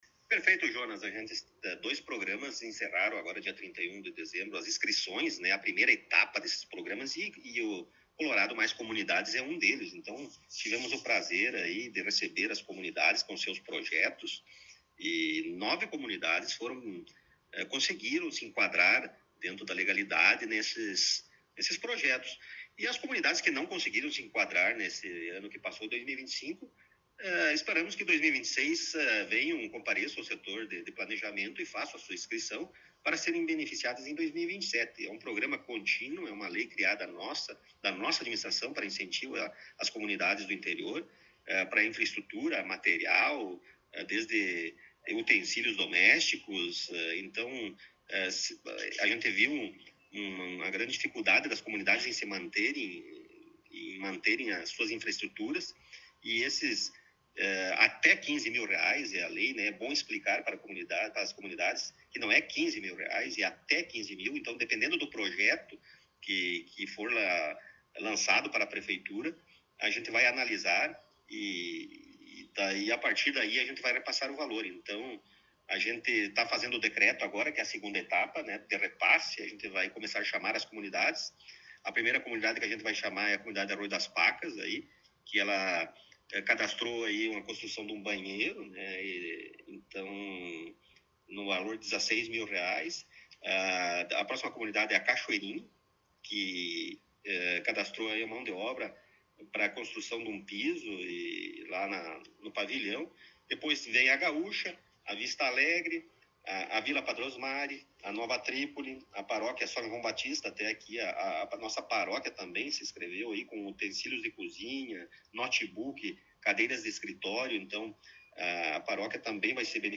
No início deste novo ano, tivemos a oportunidade de entrevistar o prefeito Rodrigo Sartori em seu gabinete na Prefeitura Municipal.